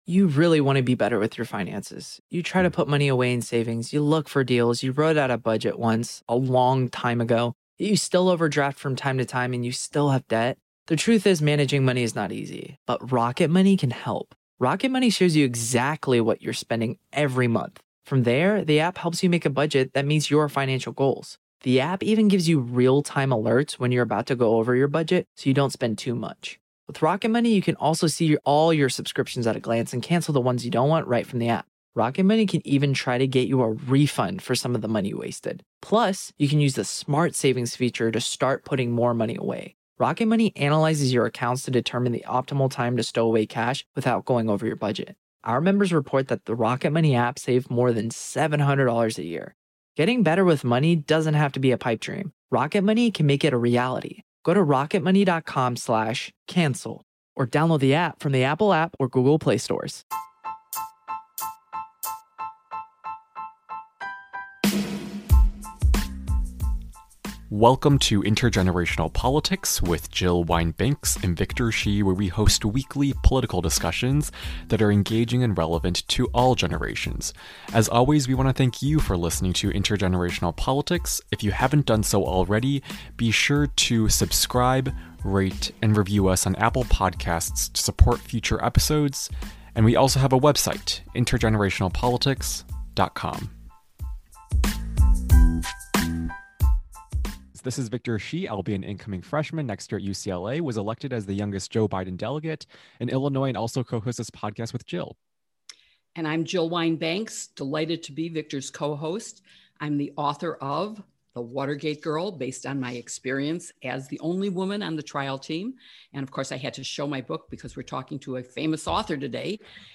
dedicated to engaging all generations in politics with weekly unfiltered conversations with experts across the nation.